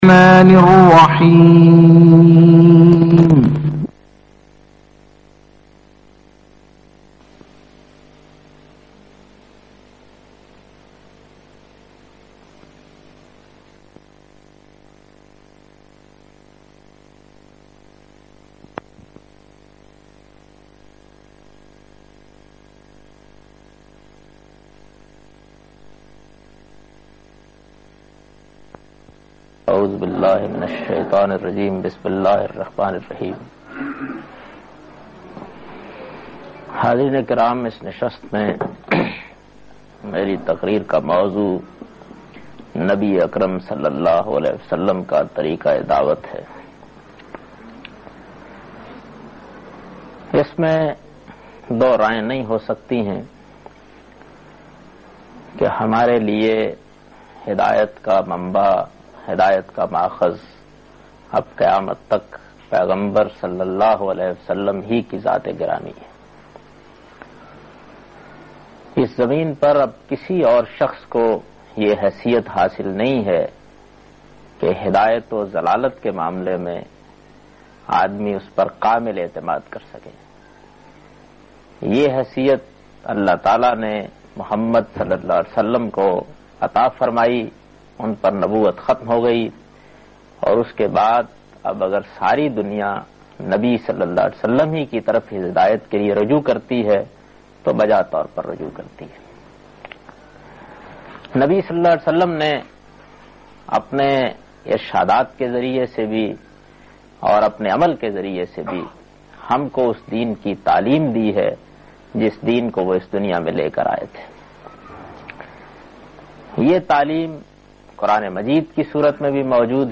Lecture by Javed Ahmad Ghamidi on the topic-Nabi Sallalah-ho-Aleh Wasalam ka Tarika Dawat